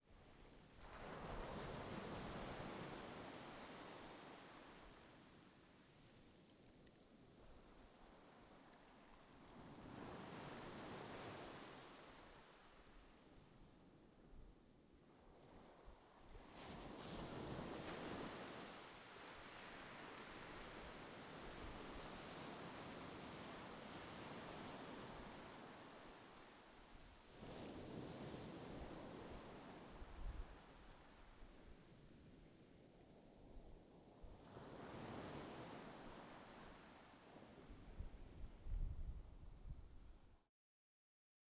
beach-tide-48k.wav